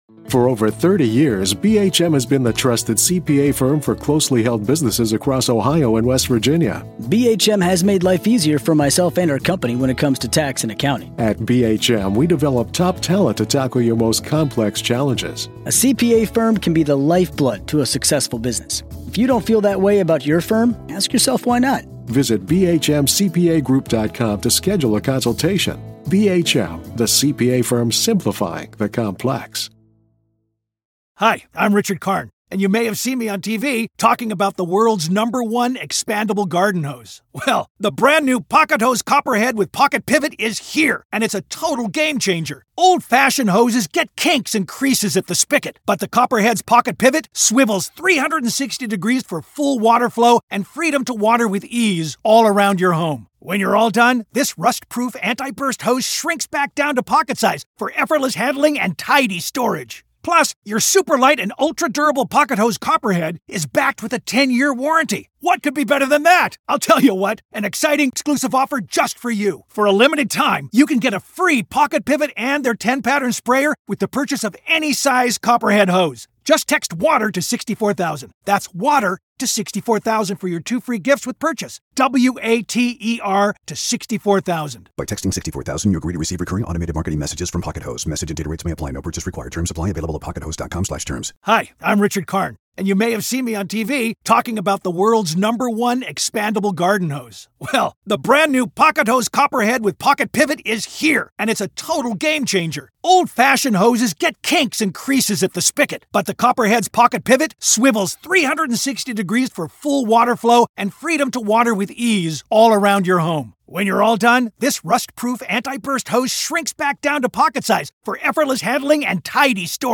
Inside The Mind Of BTK: Katherine Ramsland Interview Part 1